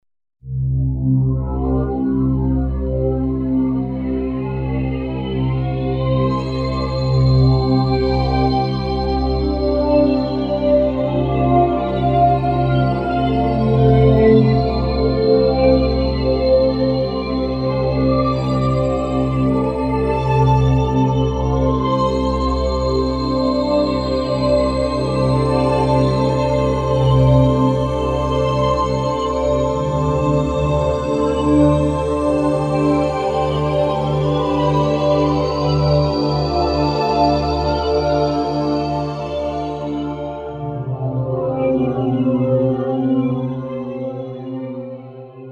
Z-plane Synthesizer (1993)
deep pad